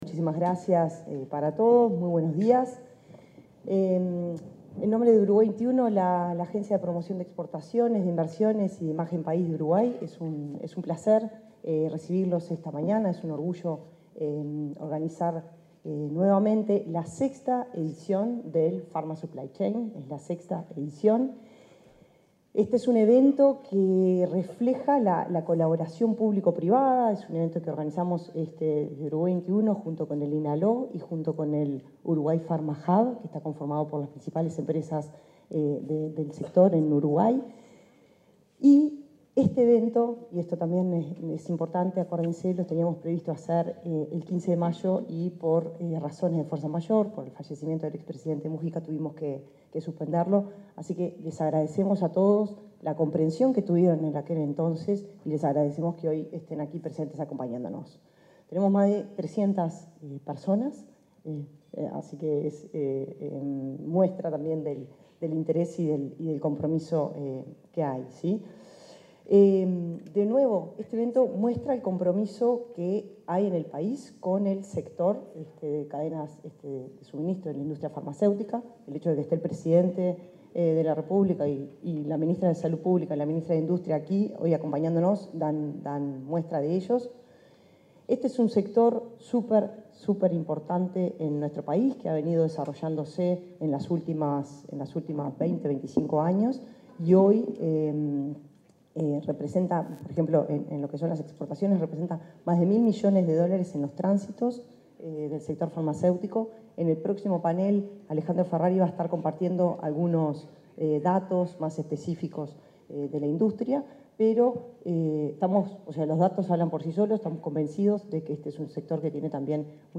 Palabras de autoridades en evento Uruguay Best Practices in Pharma Supply Chain 2025